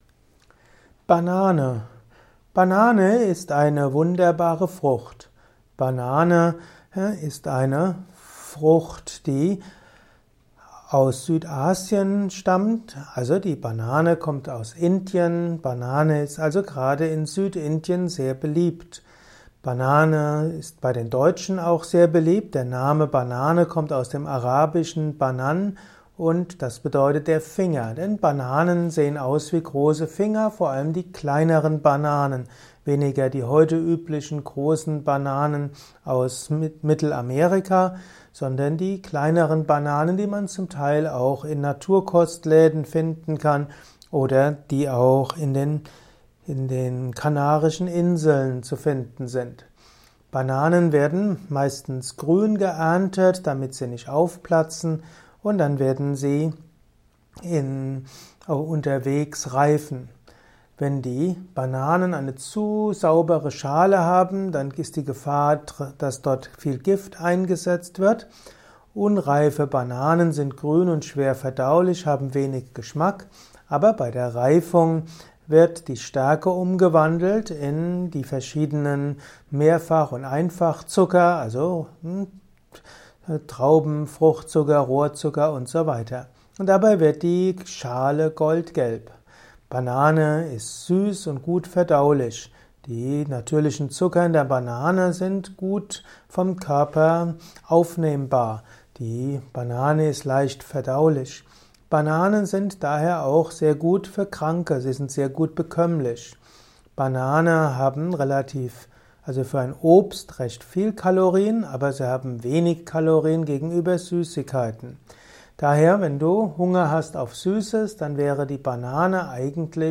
Kompakte Informationen zu Bananen in diesem Kurzvortrag